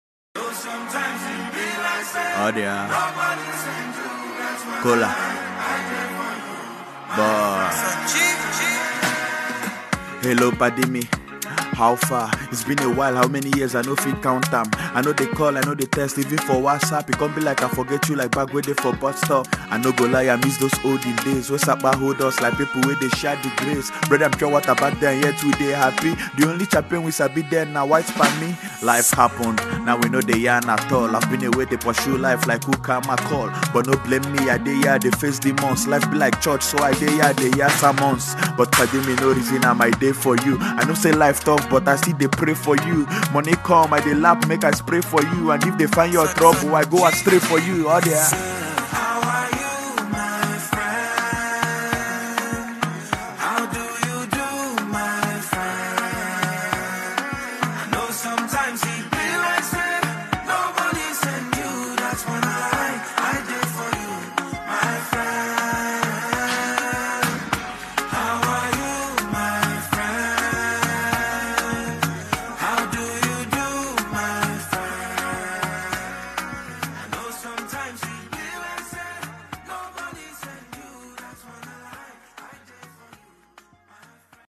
A catchy song